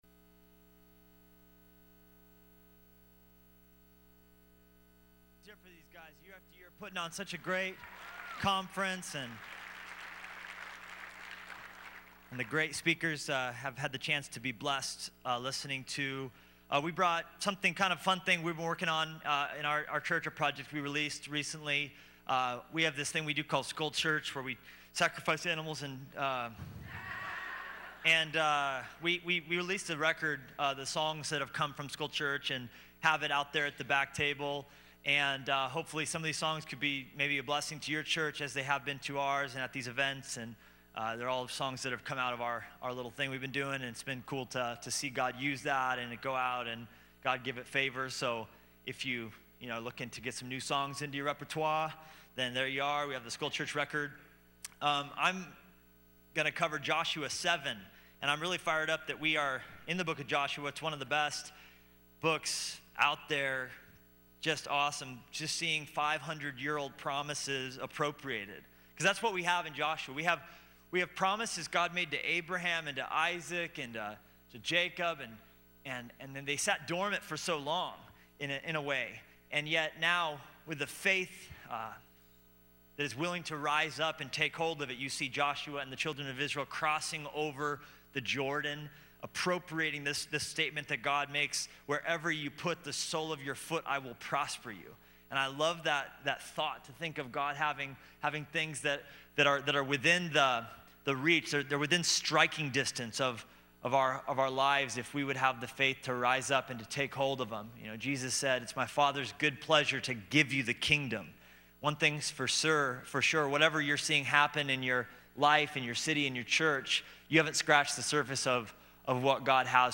at the 2013 SW Pastors and Leaders Conference